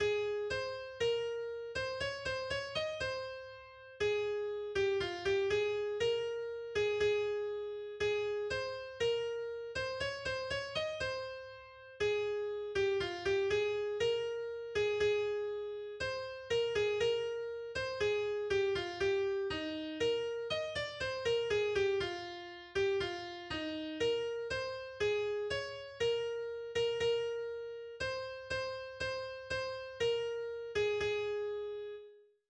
Weihnachtslied